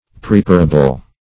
Search Result for " preparable" : The Collaborative International Dictionary of English v.0.48: Preparable \Pre*par"a*ble\, a. Capable of being prepared.